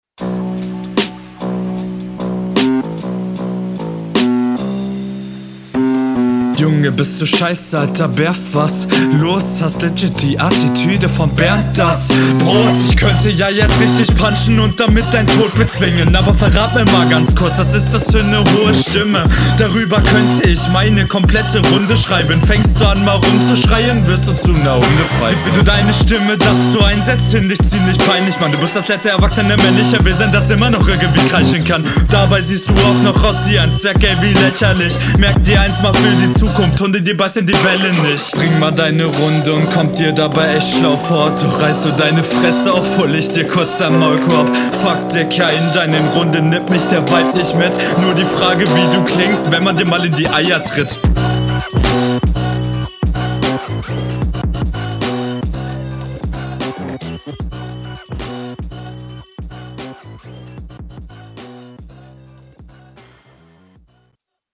Flowlich sehr sauber, gutes Ding Gegnerbezug ist stark, kann nicht meckern Punches sind gut und …